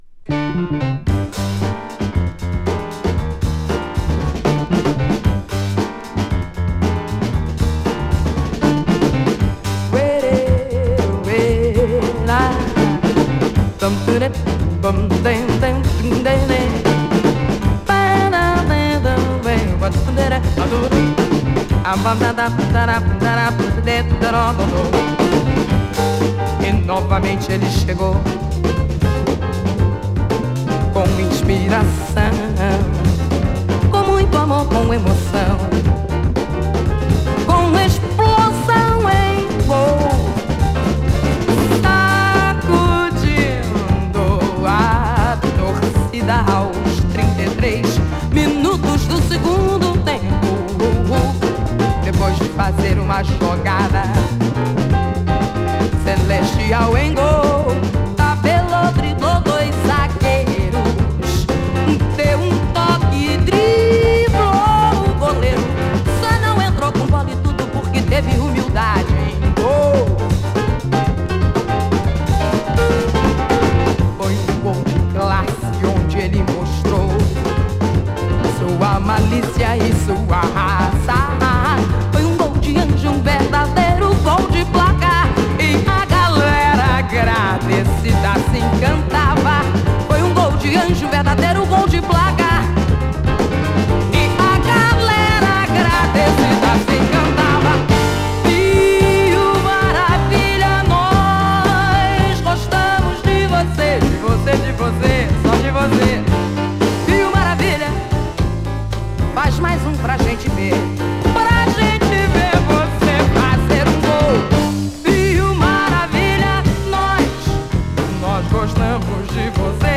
ジャズ・ファンク調のキラー・ブレイクビーツ